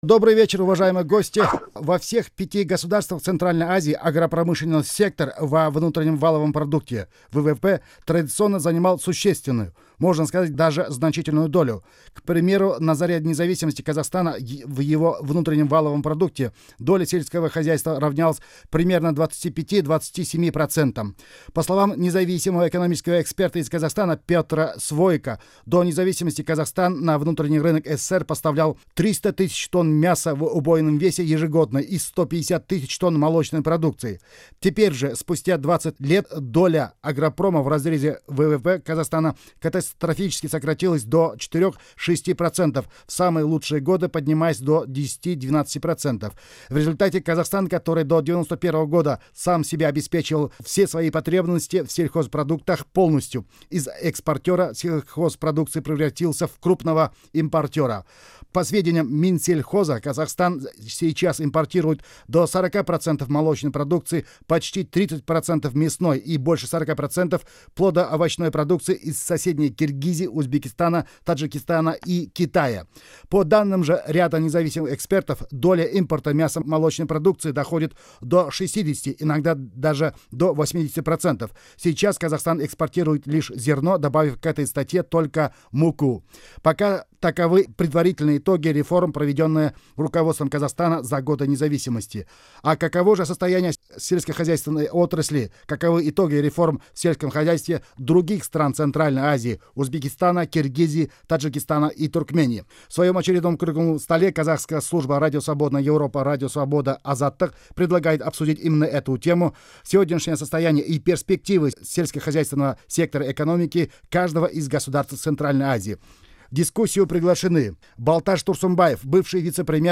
Аудиозапись круглого стола по аграрному сектору в ЦА